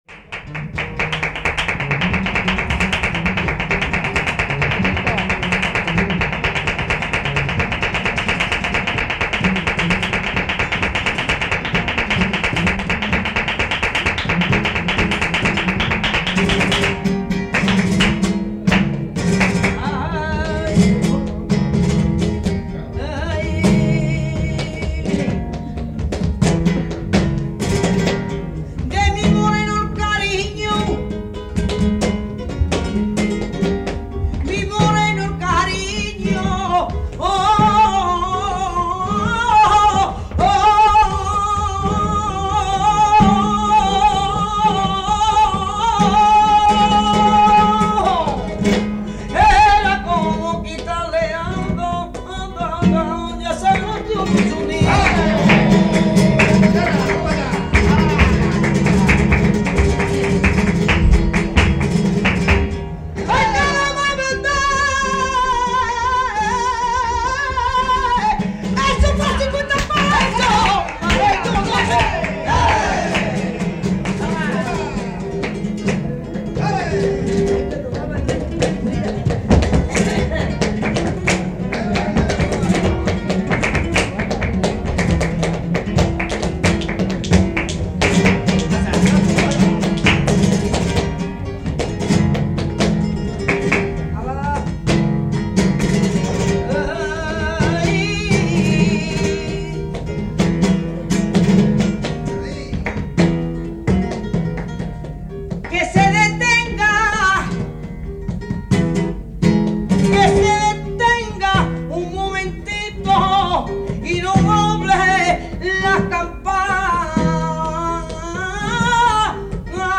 Guitare
Bulerías 3